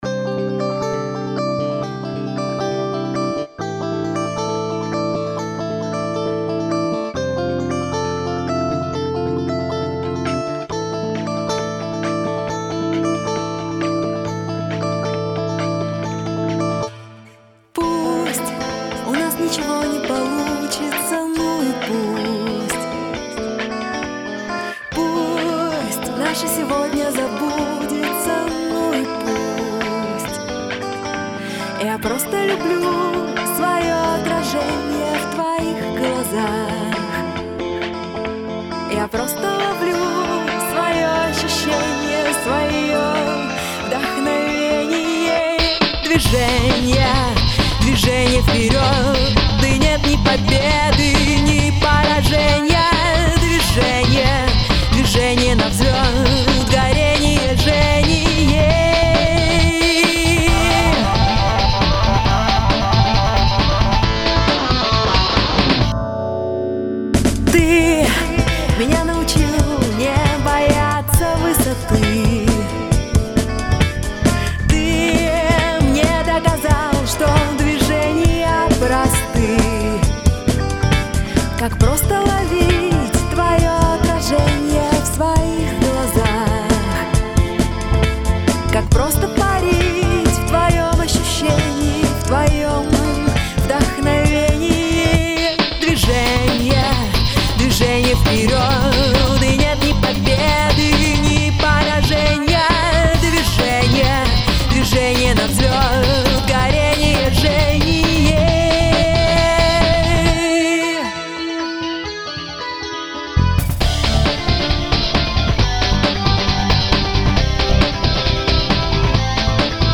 New York Russian Singer 1
New-York-Russian-Singer-1-Dvizhenie.mp3